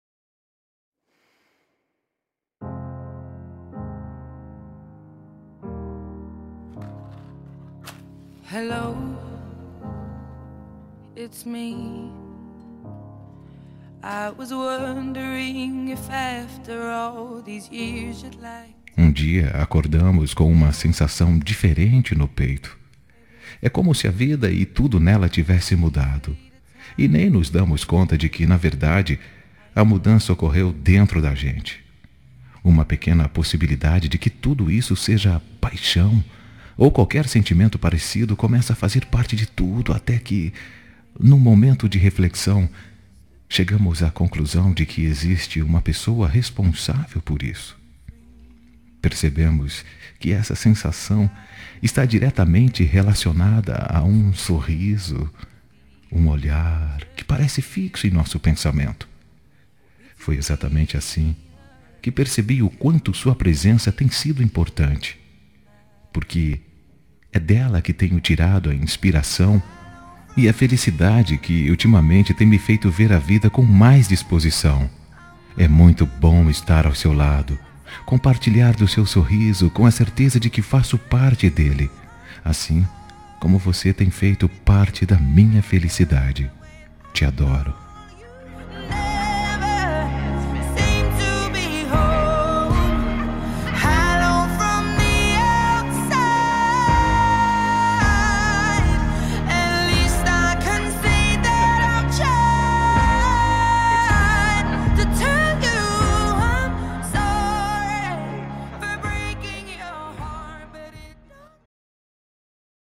Telemensagem Início de Namoro – Voz Masculina – Cód: 751